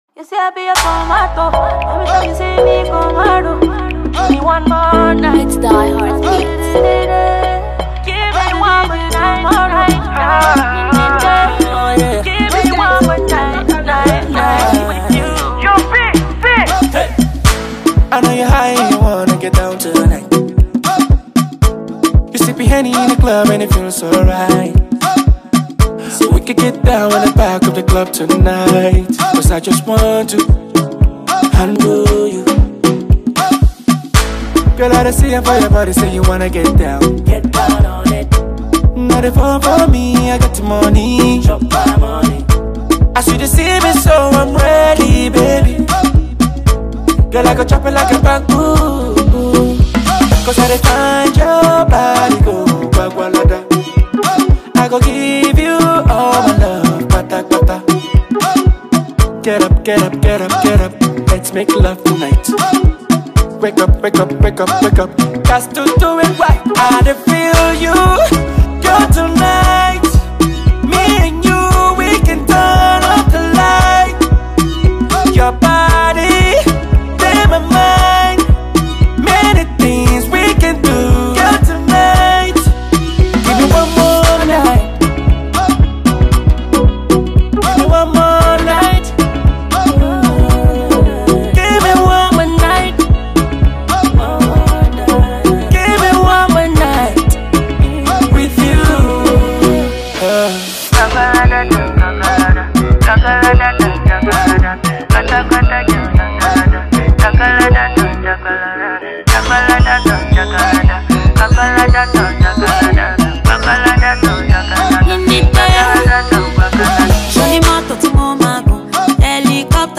a groovy vibe that will make you wanna get down on it